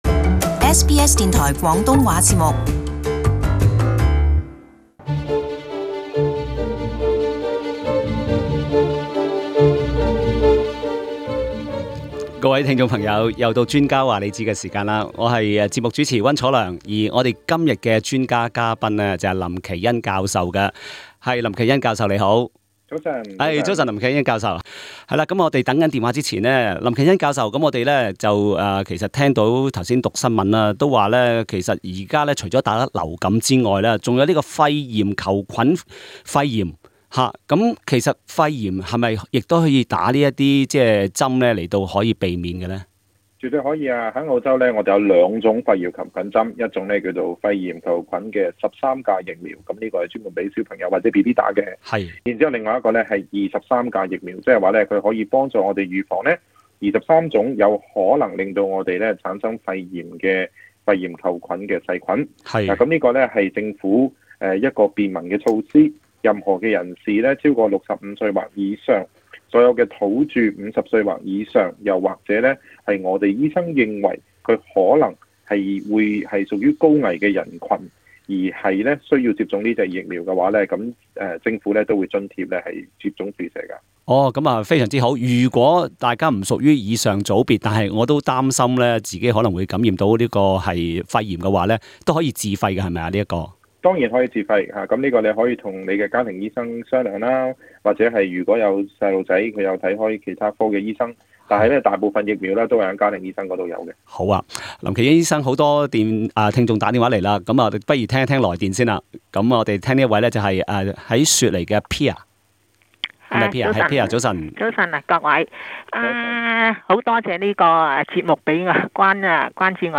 另外他也解答多為聽衆來電。